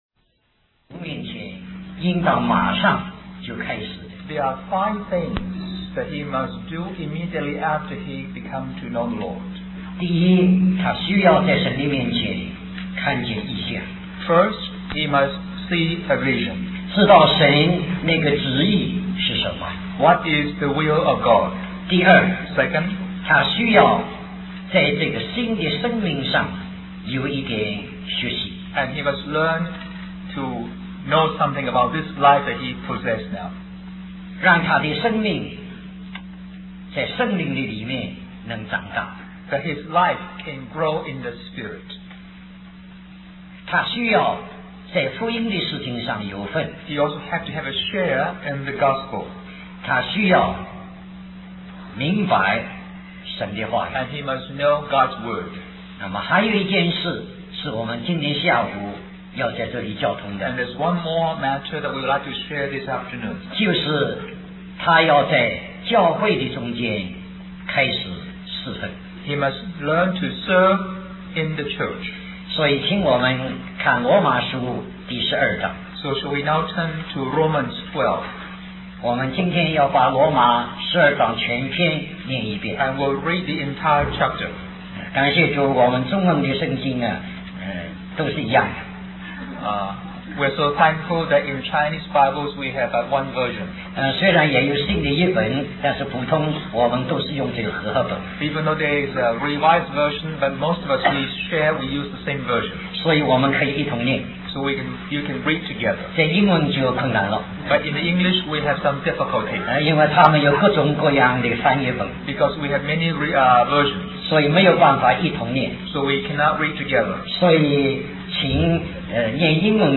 Special Conference For Service, Hong Kong